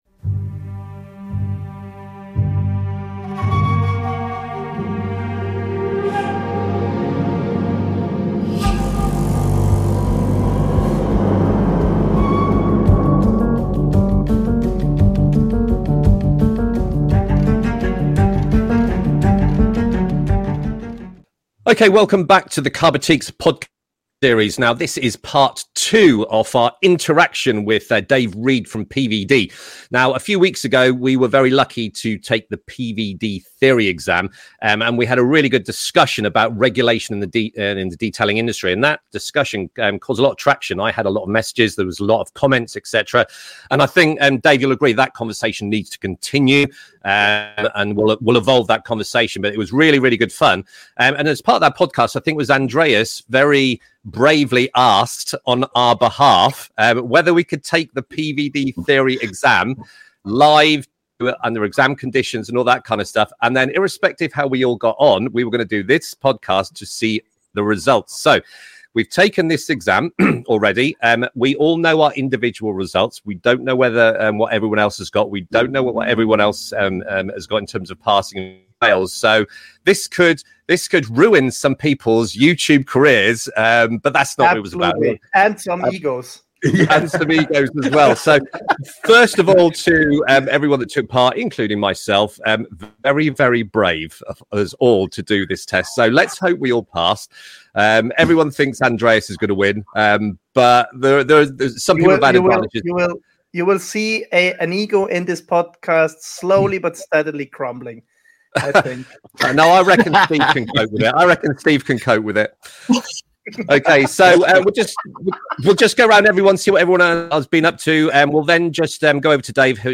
Nobody knows who has passed so this podcast has live results and reactions.